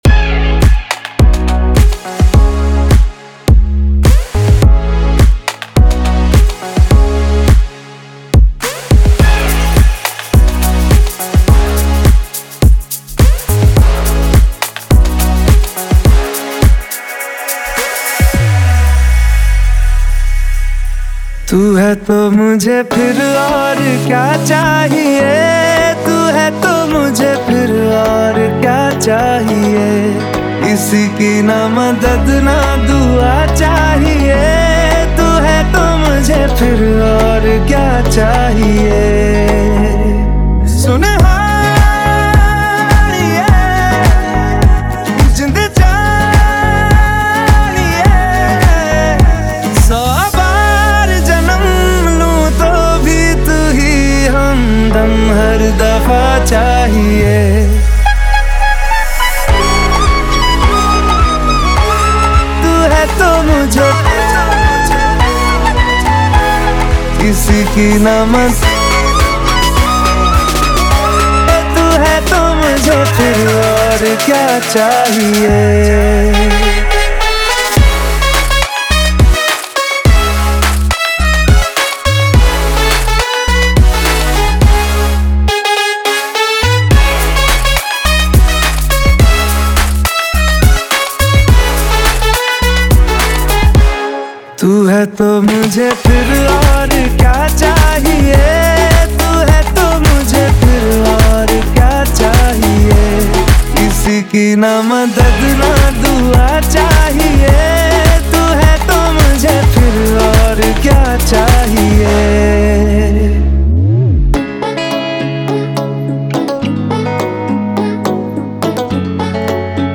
2025 Bollywood Single Remixes Song Name